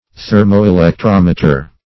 Search Result for " thermoelectrometer" : The Collaborative International Dictionary of English v.0.48: Thermoelectrometer \Ther`mo*e`lec*trom"e*ter\, n. [Thermo- + electrometer.] (Physics) An instrument for measuring the strength of an electric current in the heat which it produces, or for determining the heat developed by such a current.
thermoelectrometer.mp3